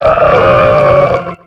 Cri de Wailmer dans Pokémon X et Y.